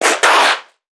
NPC_Creatures_Vocalisations_Infected [109].wav